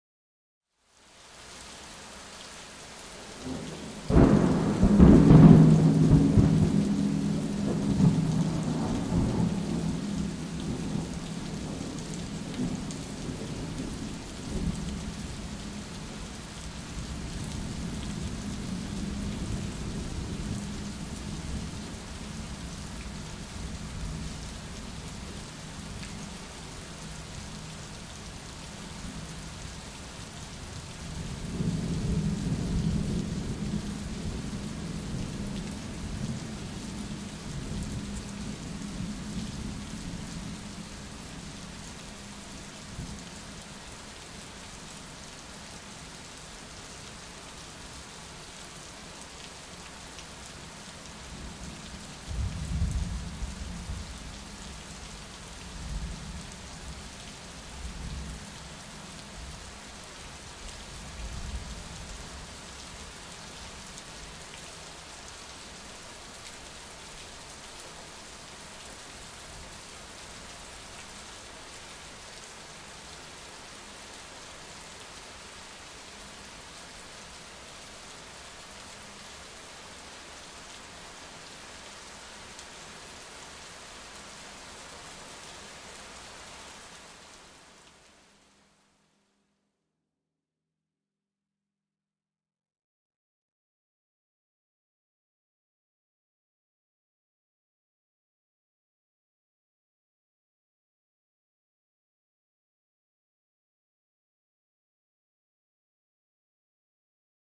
加沙 " 酒店楼梯外的大风和远处工作人员准备东西的声音隔夜1 加沙 2016年
Tag: 楼梯 酒店 外面